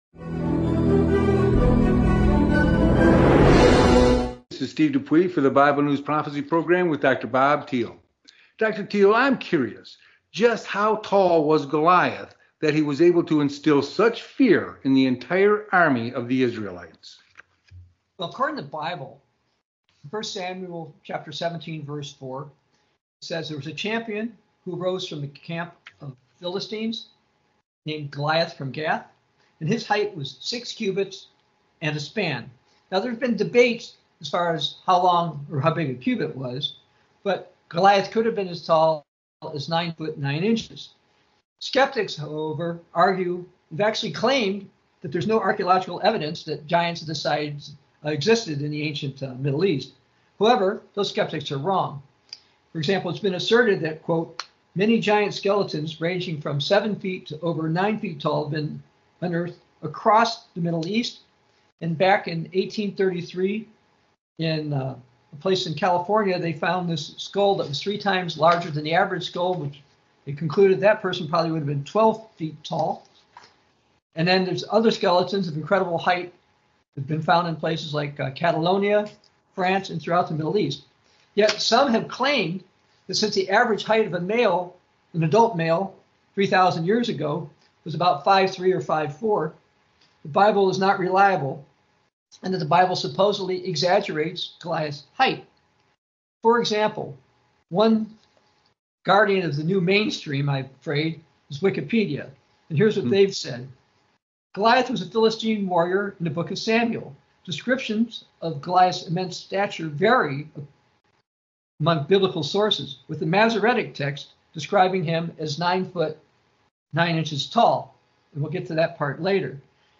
Talk Show Episode, Audio Podcast, Bible News Prophecy and Could Goliath Have Been 9 Feet Tall on , show guests , about Could Goliath Have Been 9 Feet Tall,Goliath,Samuel 17:4,The Bible,Bible Prophecy, categorized as Health & Lifestyle,History,Love & Relationships,News,Psychology,Religion,Inspirational,Motivational,Society and Culture